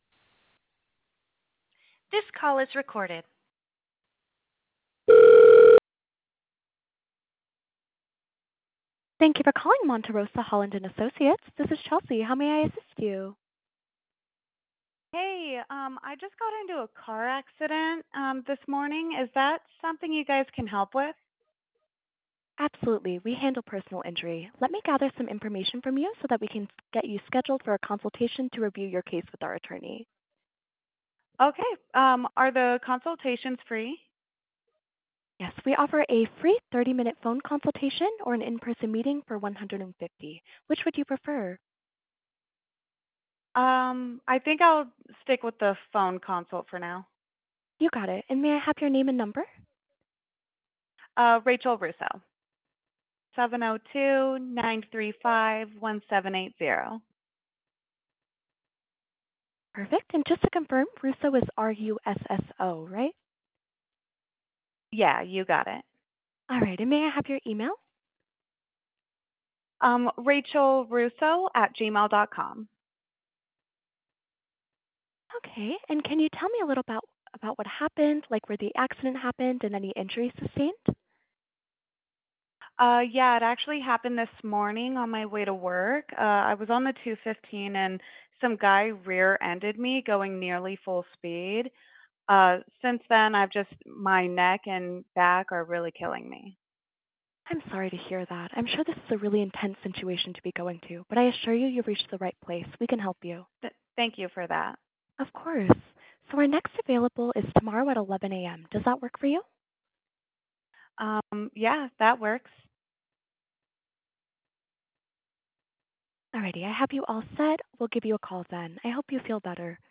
Listen to a demo to hear Abby’s virtual receptionist taking real calls like yours!
HUMAN RECEPTIONIST